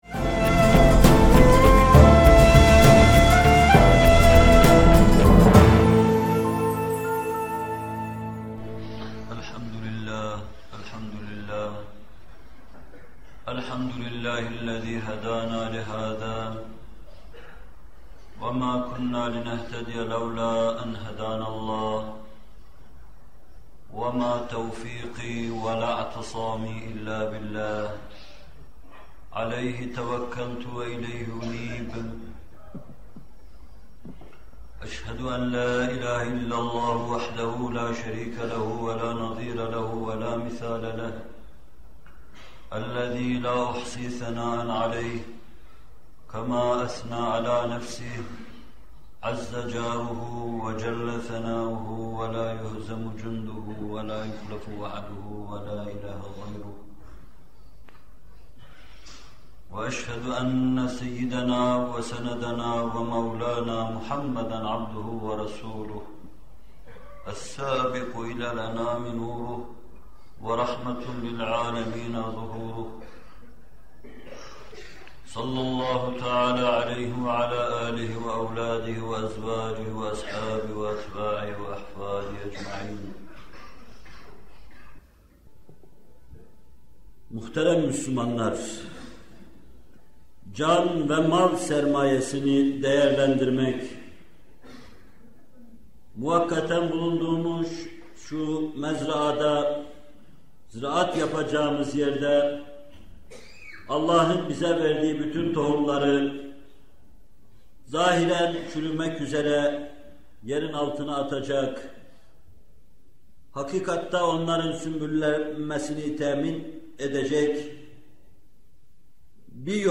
Zekat Vaazları – Zekât Hutbe -3- (21.Bölüm) - Fethullah Gülen Hocaefendi'nin Sohbetleri